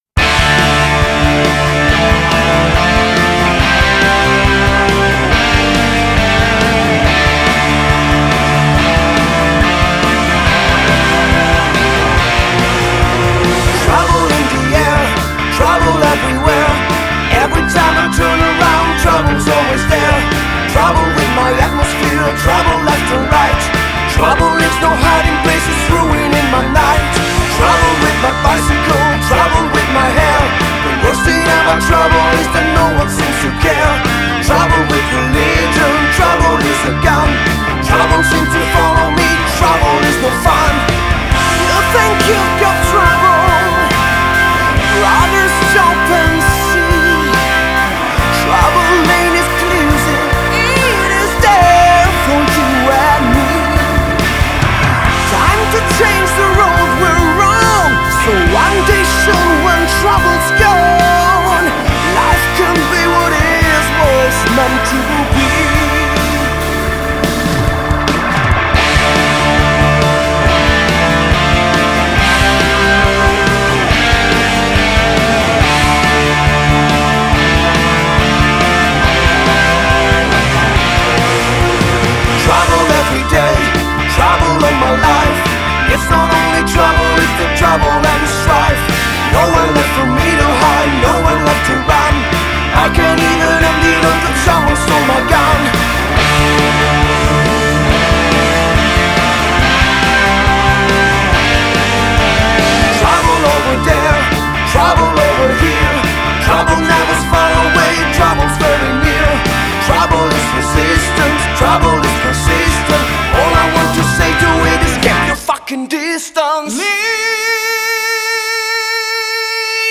Hammond Organ, Guitars, Vocals
Vocals, Bass
Guitars, Backing Vocals
Drums